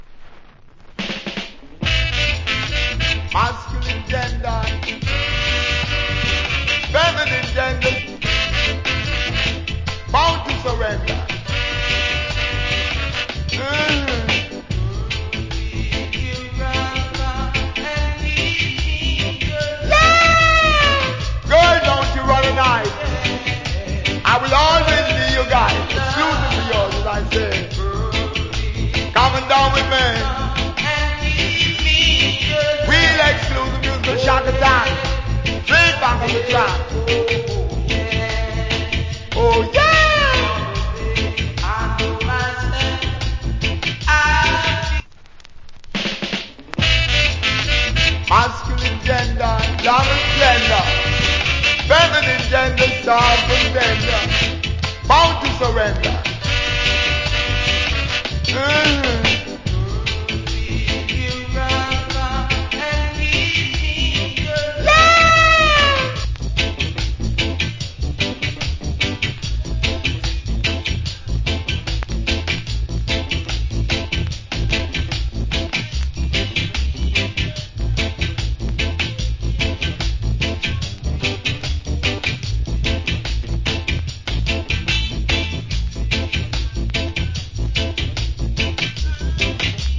Killer DJ.